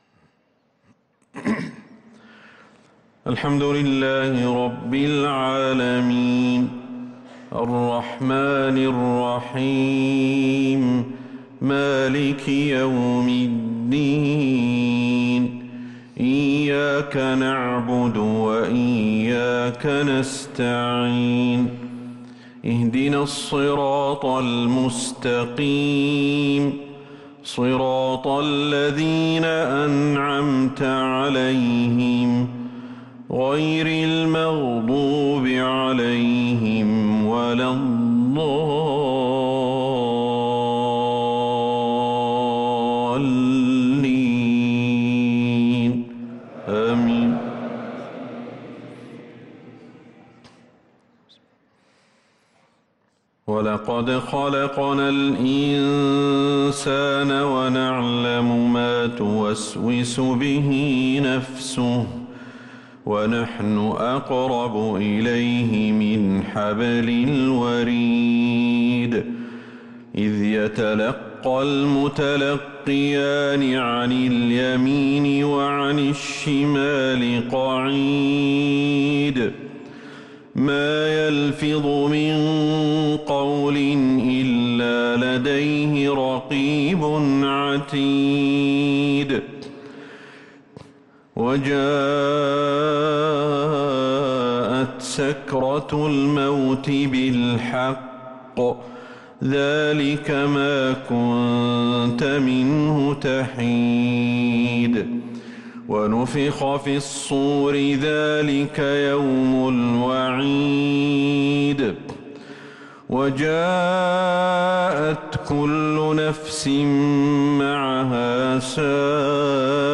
صلاة العشاء للقارئ أحمد الحذيفي 27 ربيع الأول 1444 هـ
تِلَاوَات الْحَرَمَيْن .